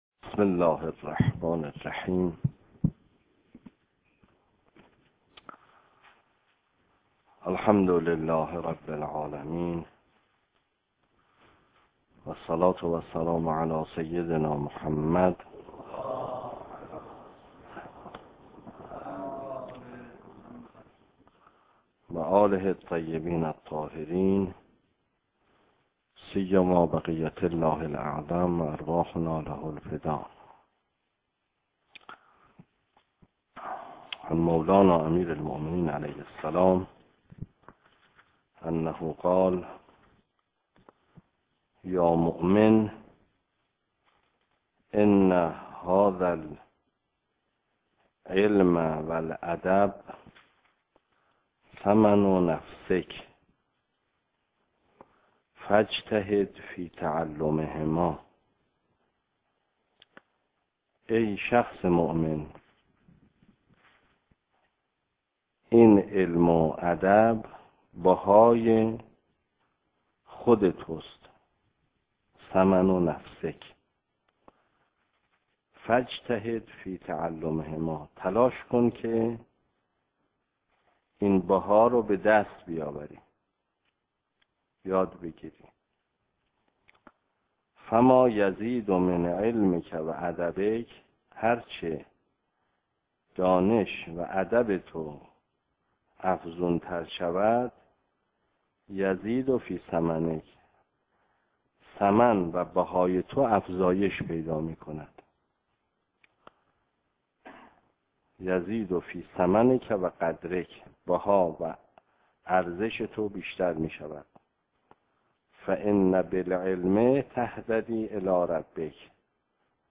درس خارج فقه استاد محمدی ری شهری - الصلاة الجمعة